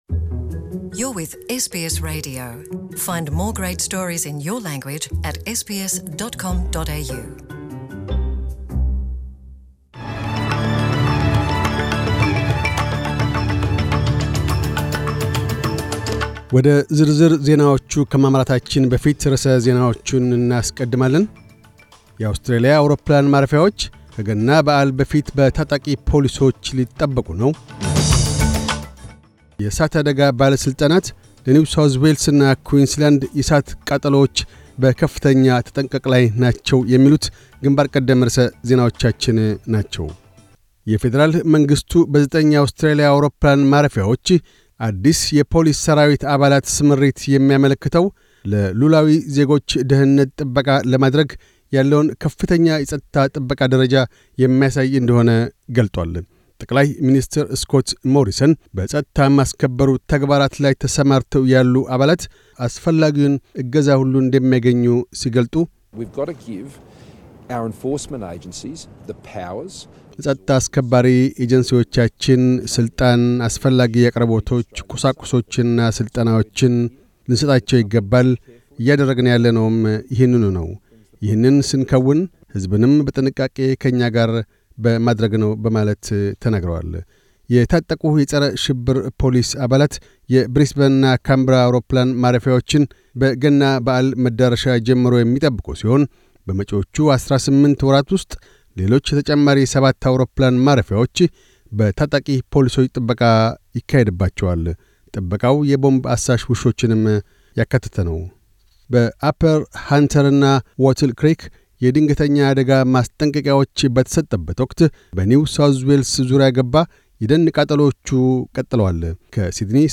News Bulletin 0612